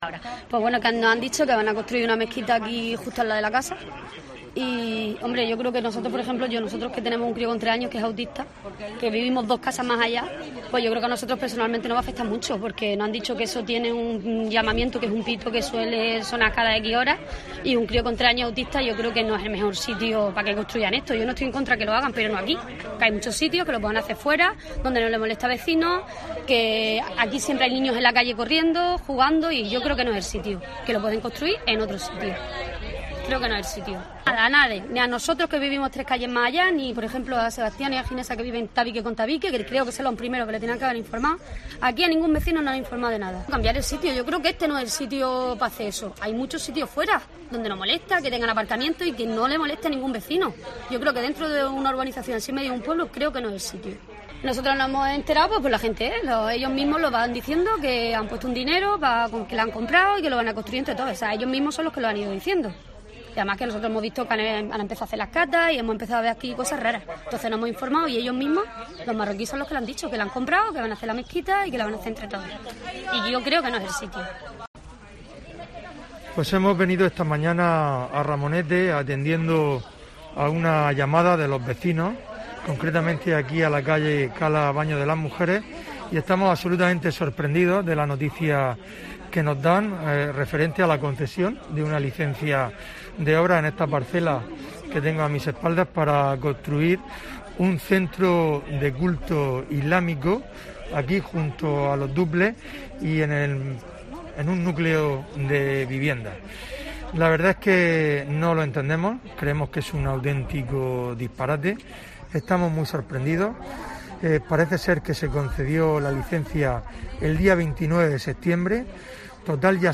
Fulgencio Gil, portavoz del PP y José Luis Ruiz, portavoz del PSOE